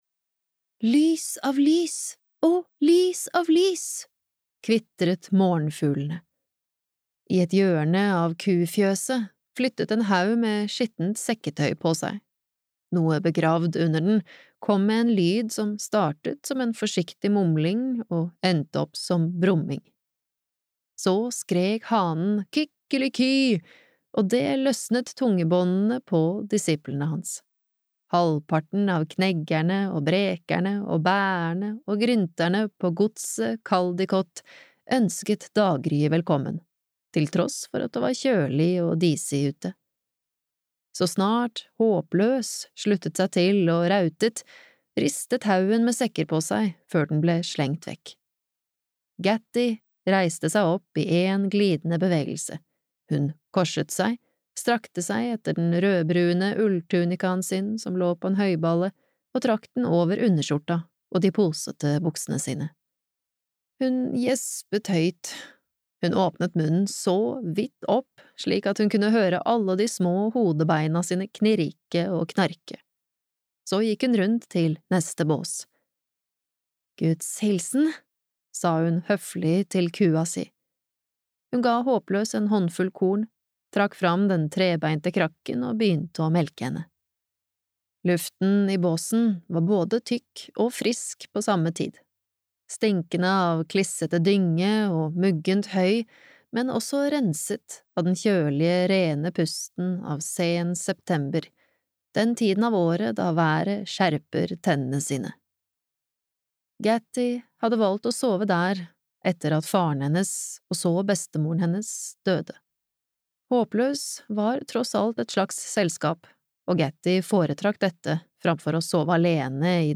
Gattys historie (lydbok) av Kevin Crossley-Holland